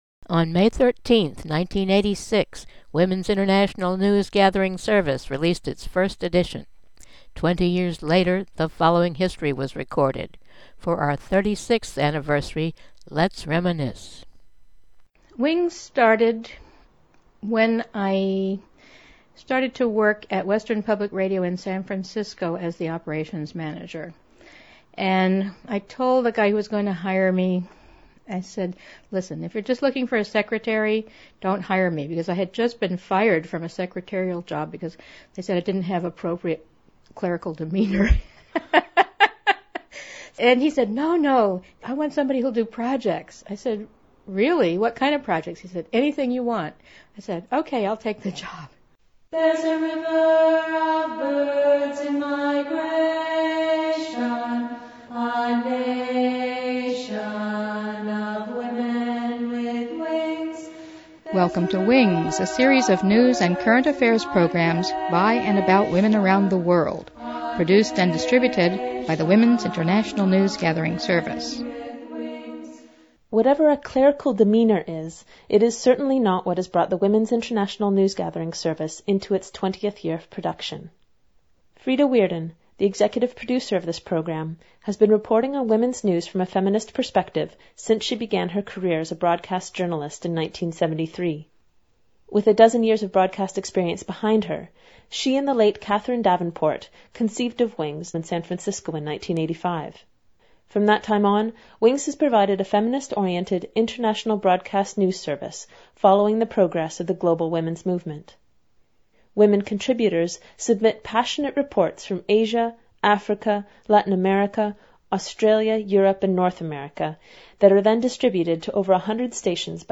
Recalling an interview from our 20th year
Mono